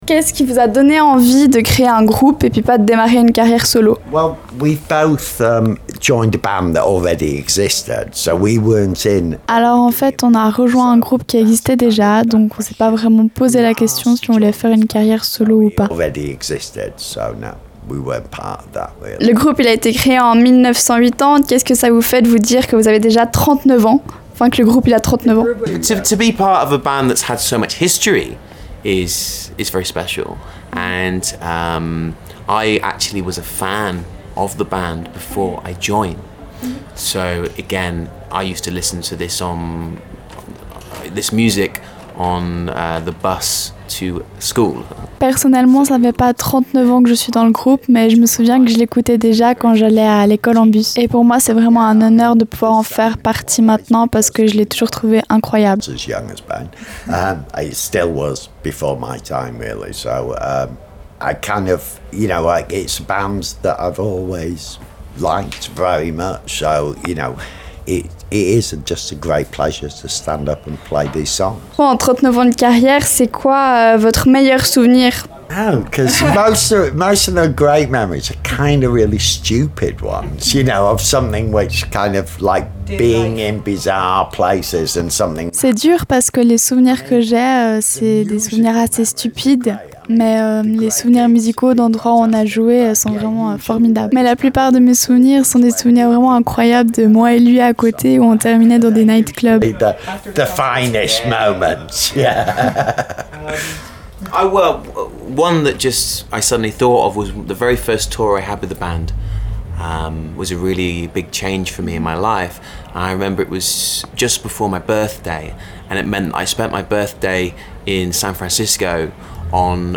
Interview – The Sisters Of Mercy